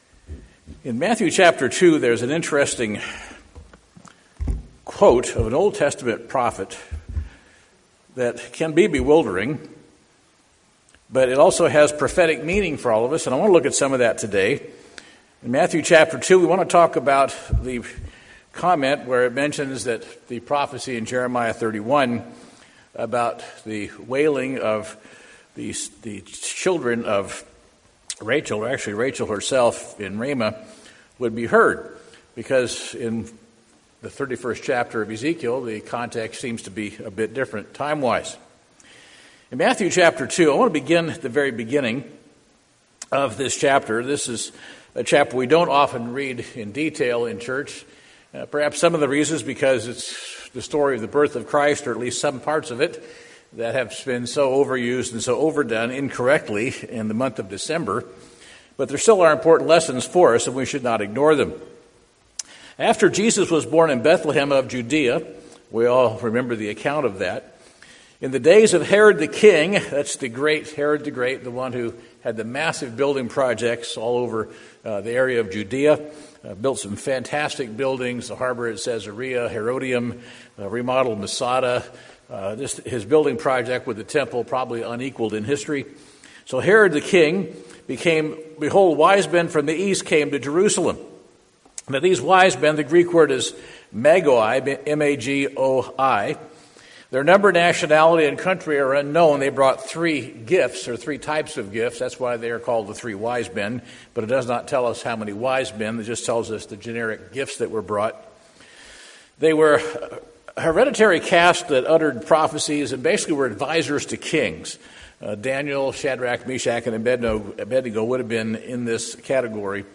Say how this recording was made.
Given in Portland, OR